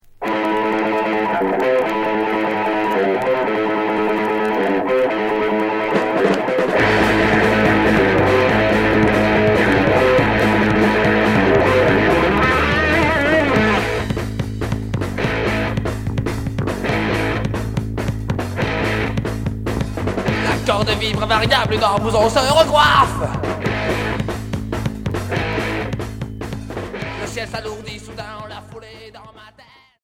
Hard punk Unique 45t retour à l'accueil